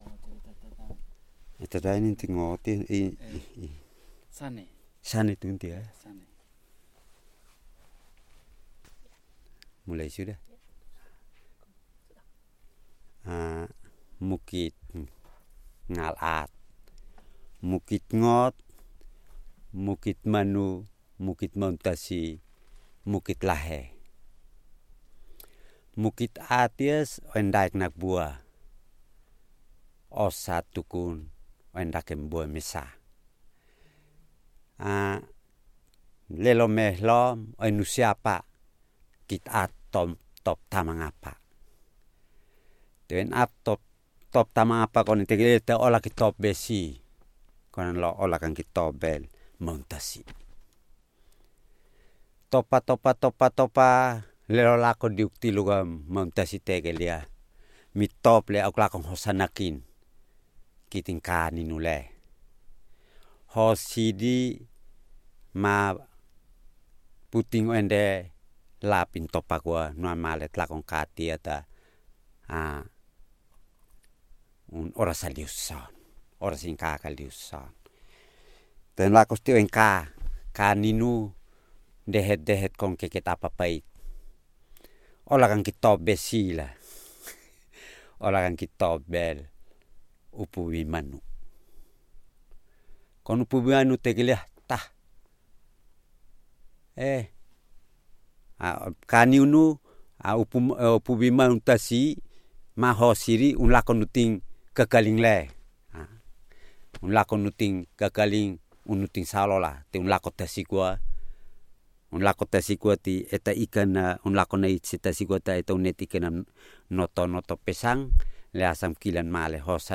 本サイトで紹介されているテキストは、ヘロン・ボロク方言の話者によるものです。